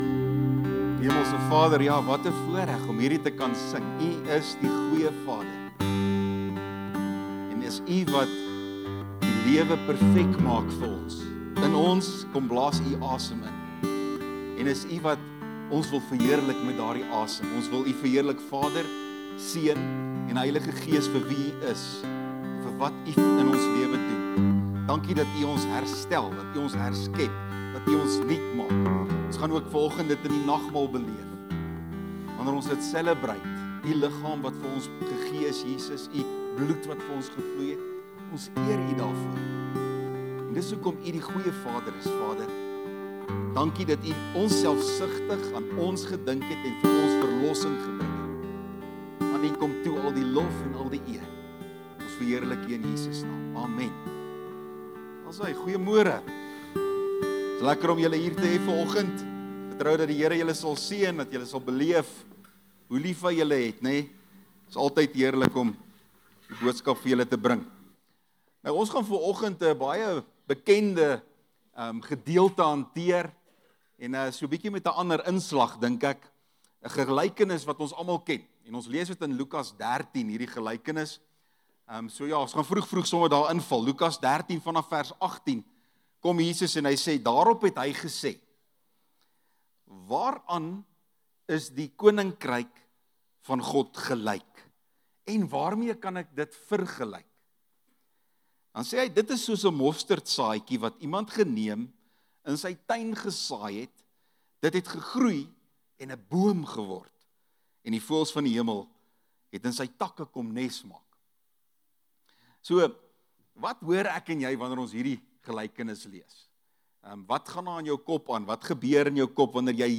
Boodskap